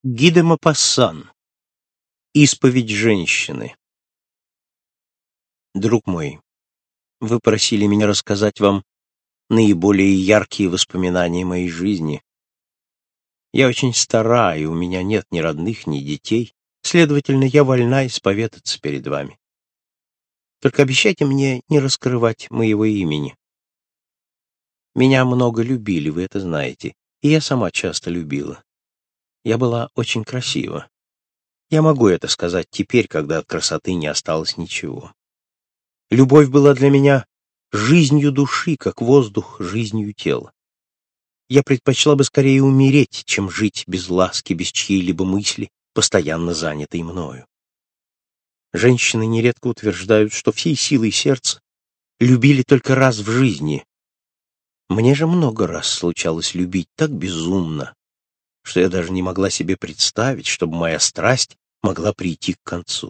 Аудиокнига Классика зарубежного рассказа № 2 | Библиотека аудиокниг